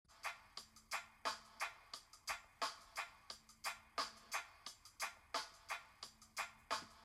first-sample-riddim.m4a